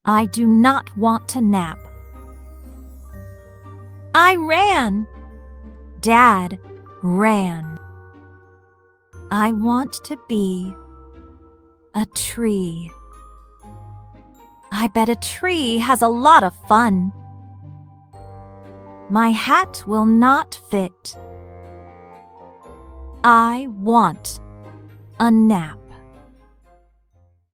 Free Adventure Decodable Reader with Final Blends | Be a Tree